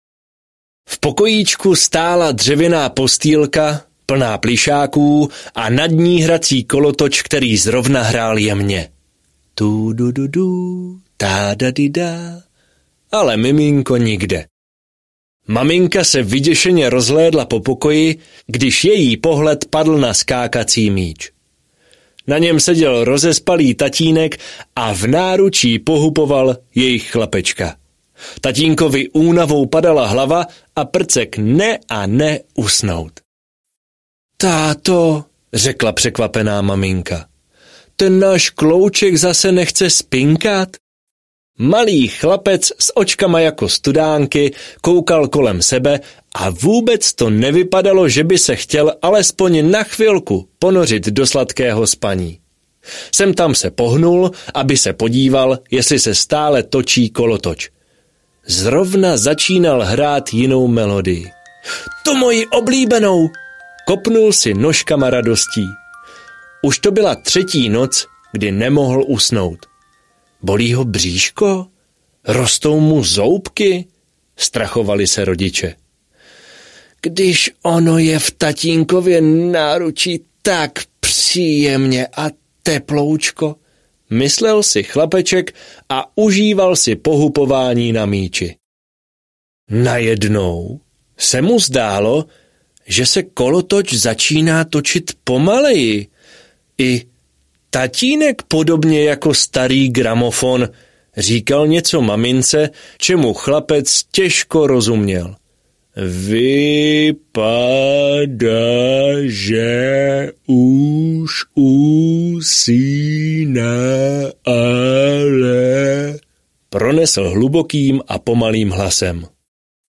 Audiokniha
Těšit se můžete i na jedno malé hudební překvapení.